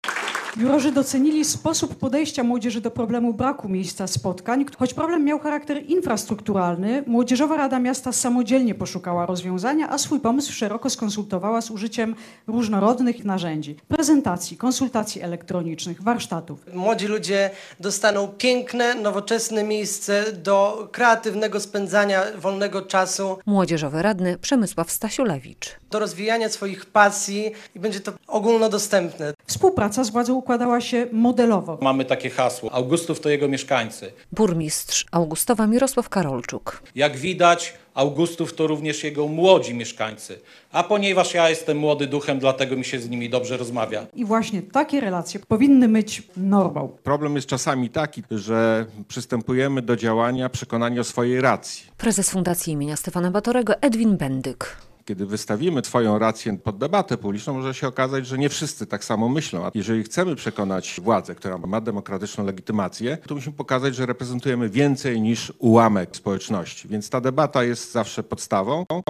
Jednymi z pięciu laureatów konkursu "Super Samorząd 2023" została Młodzieżowa Rada Augustowa i władze tego miasta - relacja